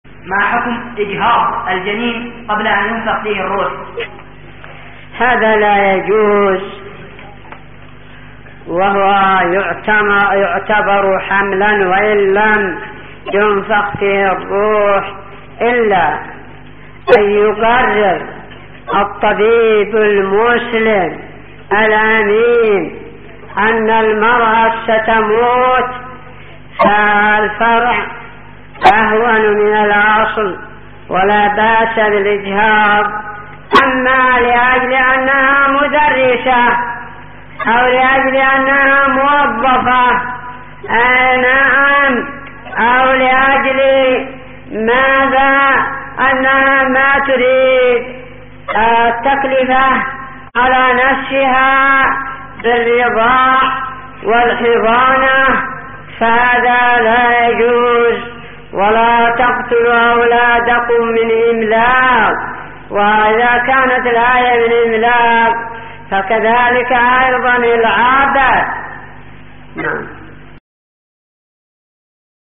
------------ من شريط : ( أسئلة شباب مسجد السلام بعدن )